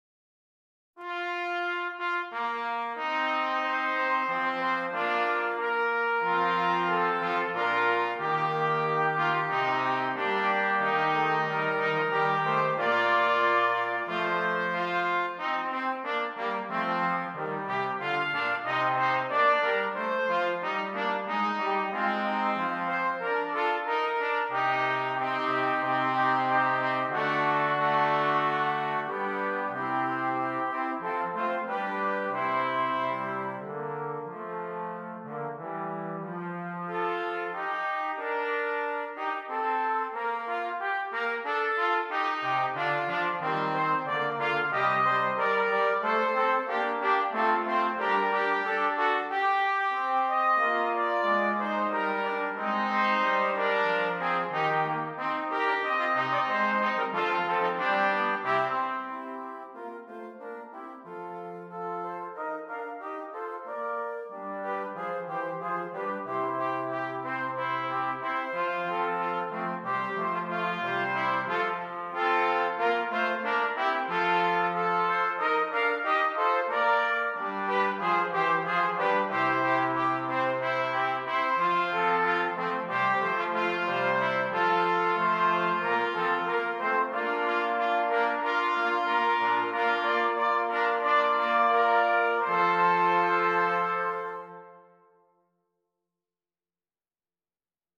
Brass Quartet